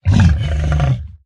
assets / minecraft / sounds / mob / zoglin / angry2.ogg
angry2.ogg